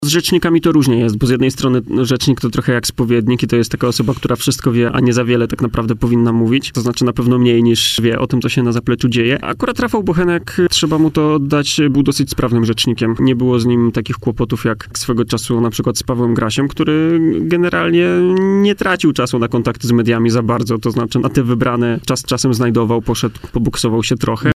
w poranku „Siódma9” na antenie Radia Warszawa